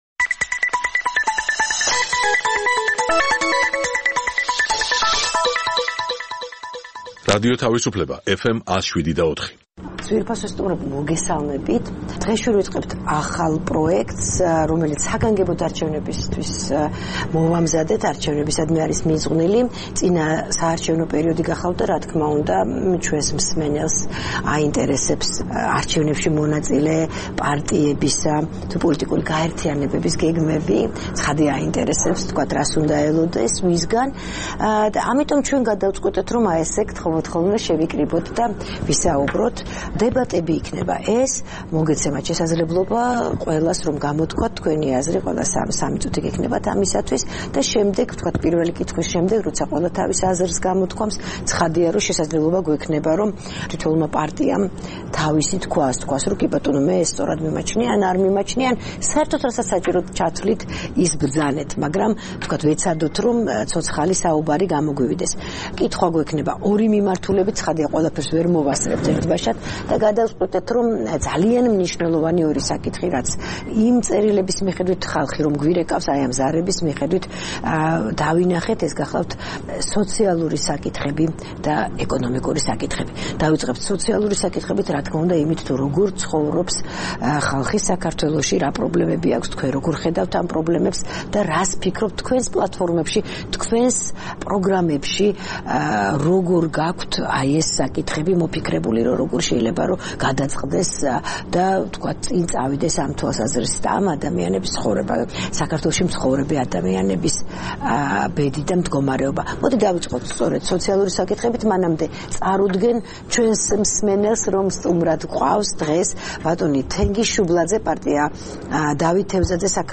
დებატები „თავისუფლებაზე“ (1)
წინასაარჩევნოდ, რადიო თავისუფლების მსმენელებს შესაძლებლობა აქვთ კიდევ ერთხელ შეხვდნენ 2016 წლის საპარლამენტო არჩევნებში მონაწილე პარტიებისა და ბლოკების წარმომადგენლებს. საგანგებო, ერთსაათიან გადაცემაში, სტუმრები ორ თემაზე საუბრობენ - სოციალურ საკითხებსა და ეკონომიკურ პრობლემებზე.